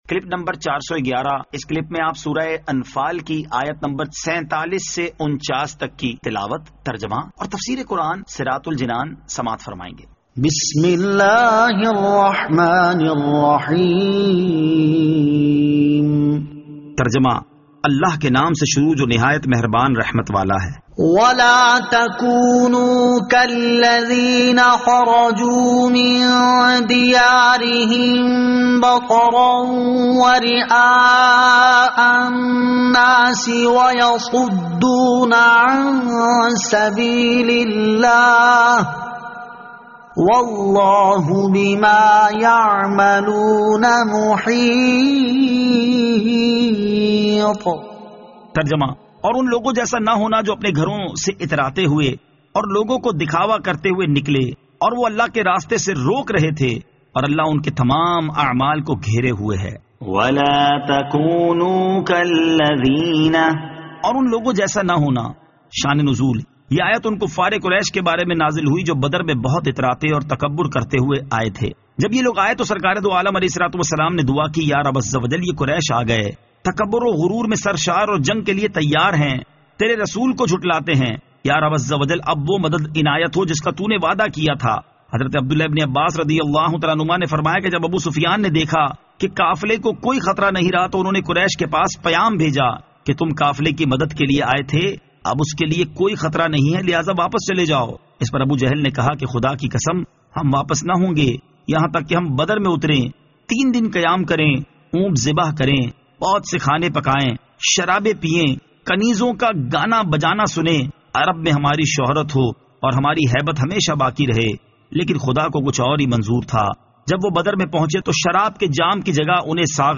Surah Al-Anfal Ayat 47 To 49 Tilawat , Tarjama , Tafseer